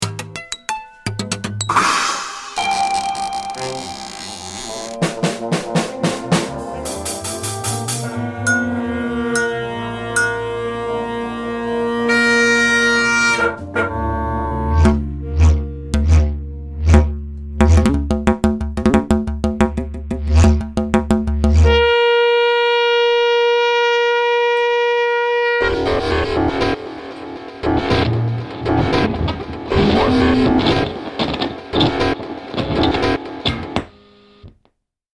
پکیج افکت صوتی موزیکال
A collection of musical elements to use as straight sound effects or source material to create something you’ve never heard before. Includes a didgeridoo, barrel organ, highschool band, shofar, guitars and more.